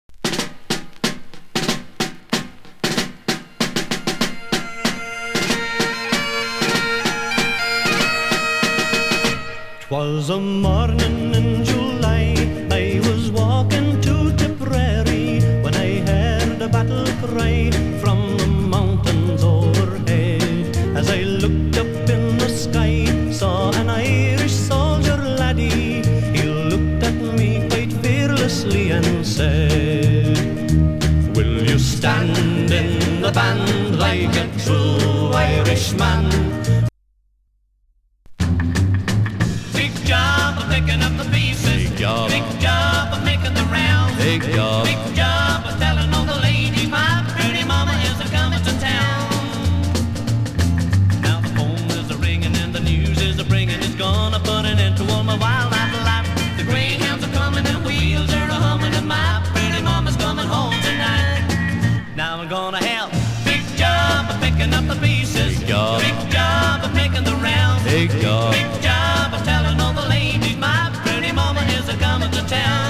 ドラムが生み出すマーチのリズムとバグパイプが織り成すイントロは広大な音楽シーンという戦場に彼等を勇ましく駆り立てる。
(税込￥1650)   IRISH